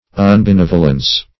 Unbenevolence \Un`be*nev"o*lence\, n.
unbenevolence.mp3